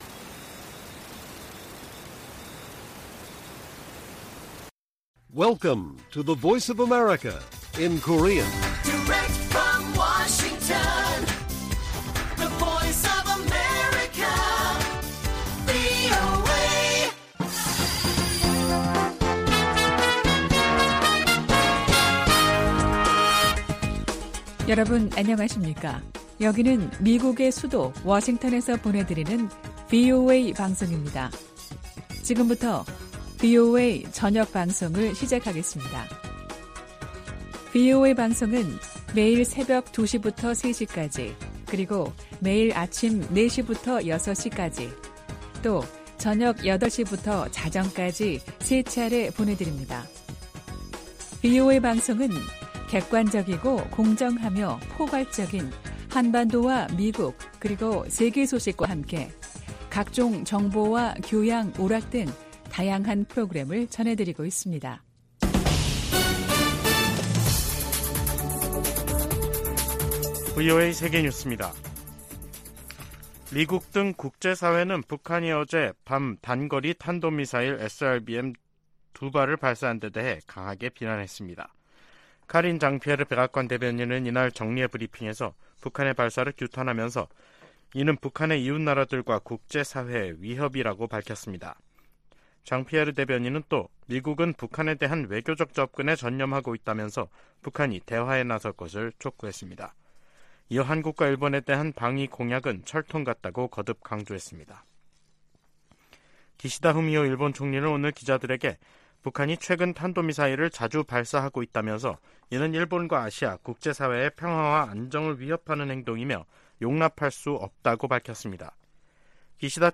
VOA 한국어 간판 뉴스 프로그램 '뉴스 투데이', 2023년 7월 25일 1부 방송입니다. 백악관과 미 국무부는 월북 미군과 관련해 여전히 북한의 응답을 기다리는 중이며, 병사의 안위와 월북 동기 등을 계속 조사하고 있다고 밝혔습니다. 북한이 24일 탄도미사일 2발을 동해상으로 발사했습니다.